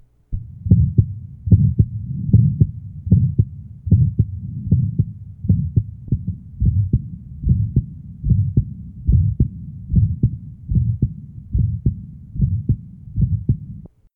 Date 1969 Type Diastolic Abnormality Prosthesis Prosthetic aortic valve with slight leak. Grade 2 edm [end diastolic murmur] noted on **/**/1969 To listen, click on the link below.
RSE (Channel A) LSE (Channel B) %s1 / %s2